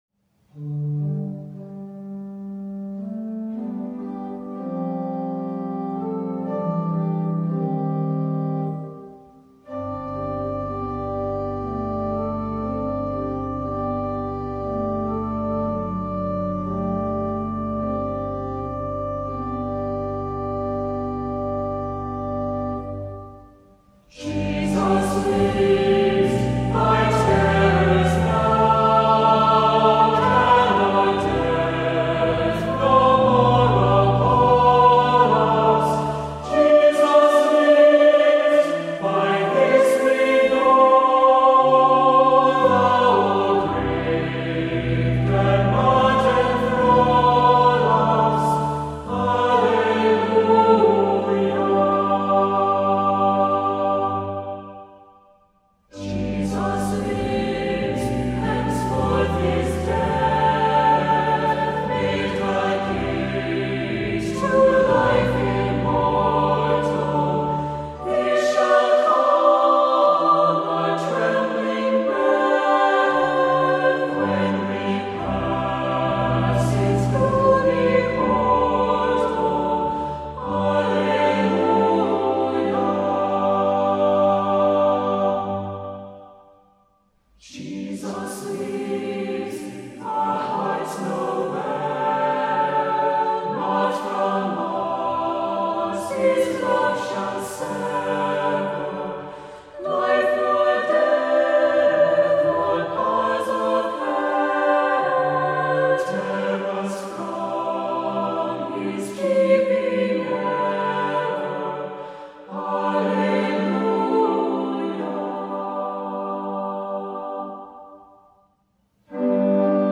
Voicing: SATB,Assembly